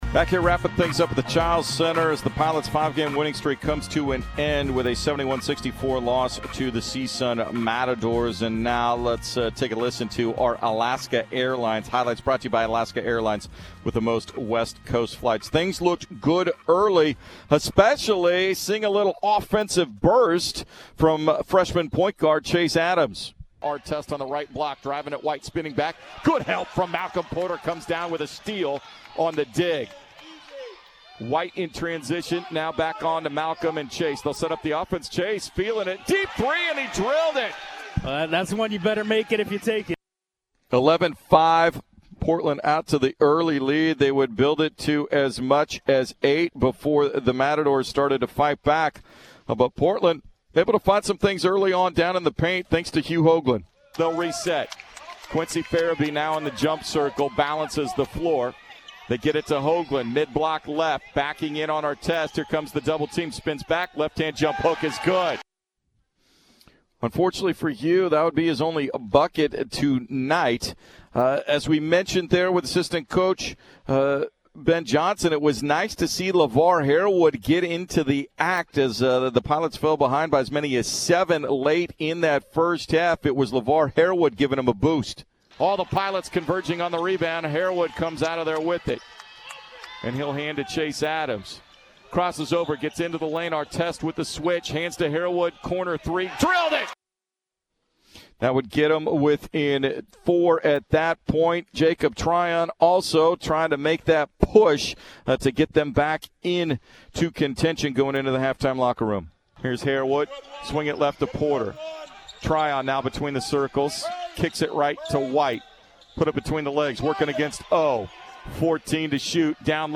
highlights from 910 ESPN-Portland